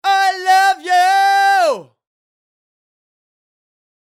Its character is often direct, loud, and shouted, like when you call ‘hey’ after someone in the street.
#111(Male)
‘OO’, ‘O’, ‘OR’, and ‘AH’ are altered to ‘OH’.